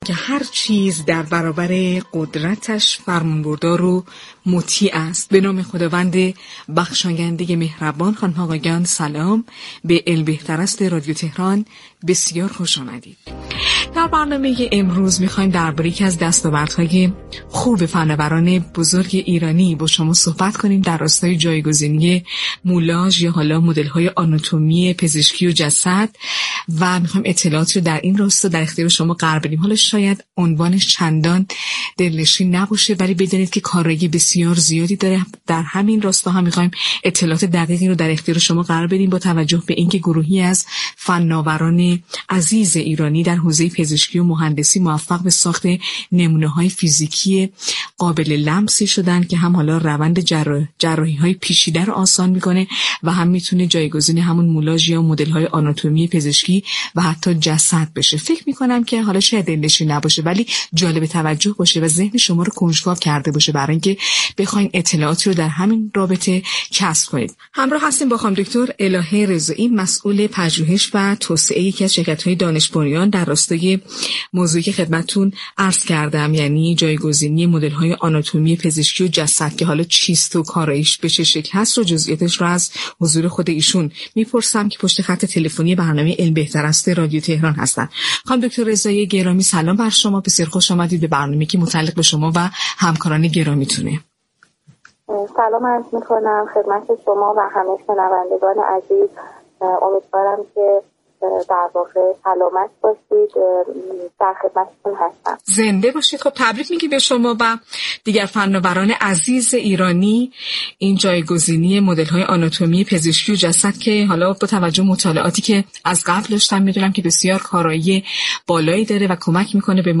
مصطفی قانعی دبیر ستاد توسعه زیست فناوری در گفت و گو با «علم بهتر است» اظهار داشت: